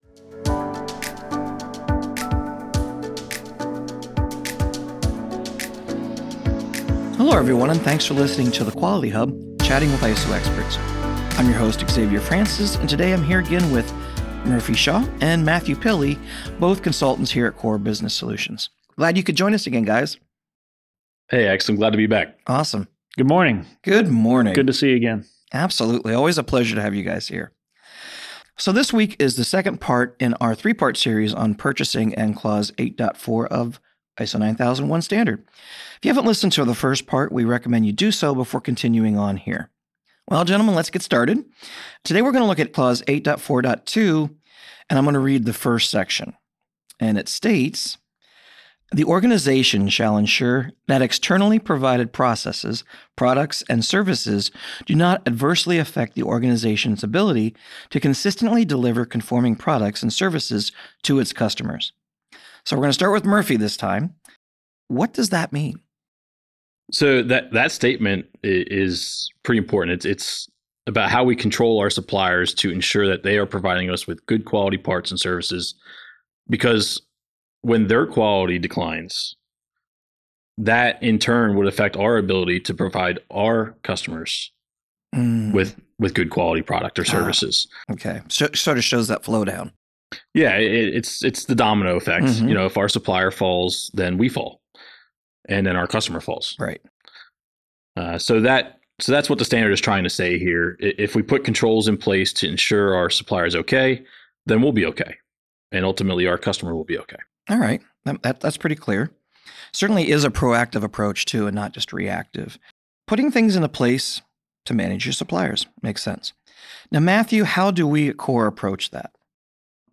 Consultants discuss ISO 9001 Clause 8.4.2 - how to control suppliers to ensure that they are providing us with good quality parts & services.